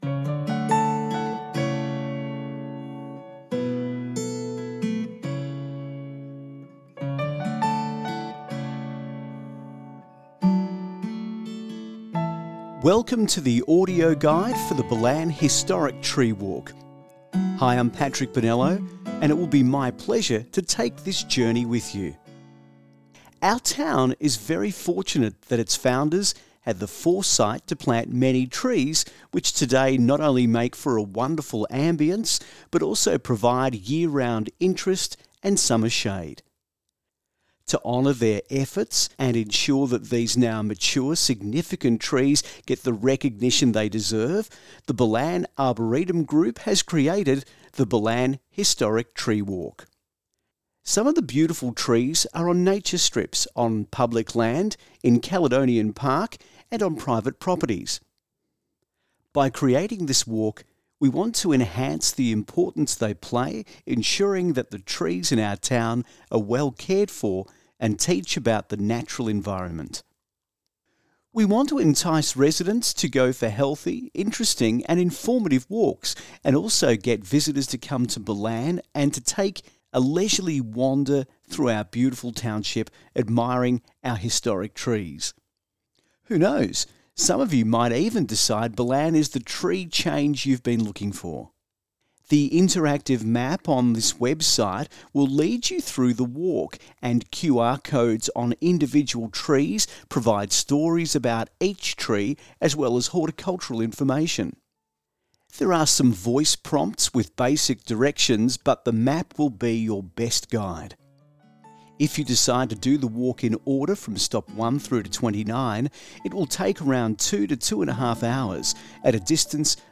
Audio Tour of the Ballan Historic Tree Walk